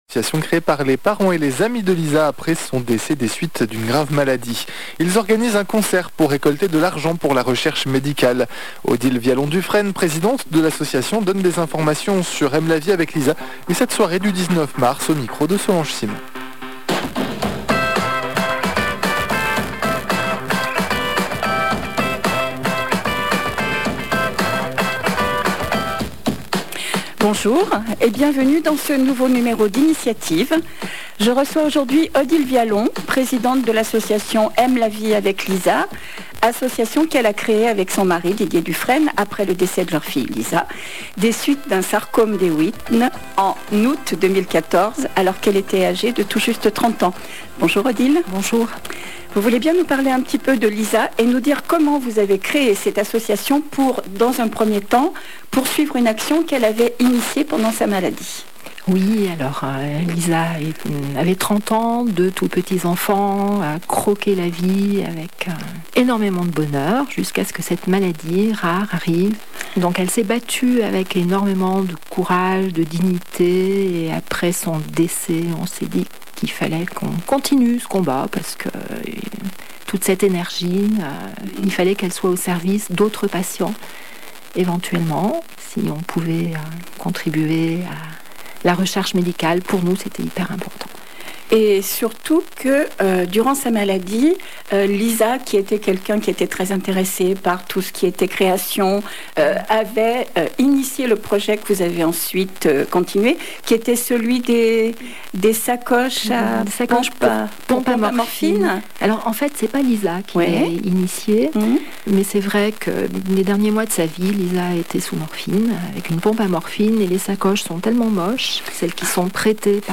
Corps à Chœur à la radio